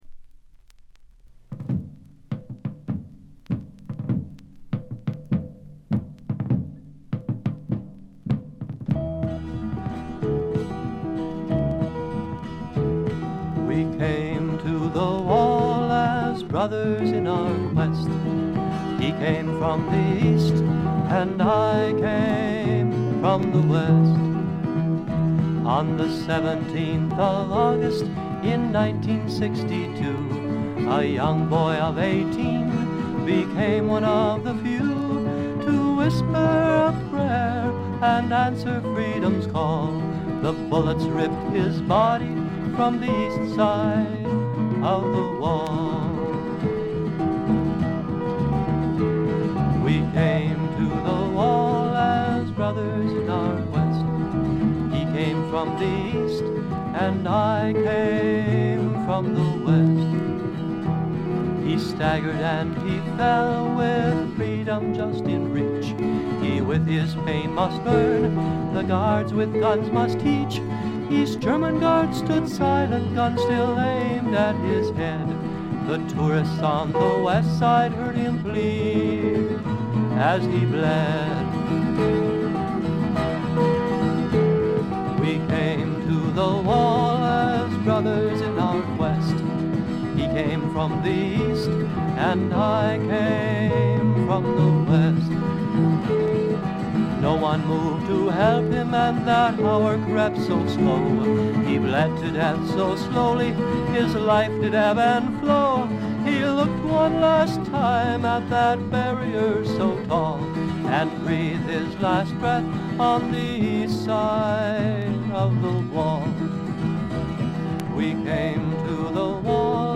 軽微なバックグラウンドノイズやチリプチ少々、プツ音2回ほど。
試聴曲は現品からの取り込み音源です。
Vocals, Twelve-String Guitar, Kazoo
Lead Guitar
Bass Guitar
Percussion
Recorded At - United Theological Seminary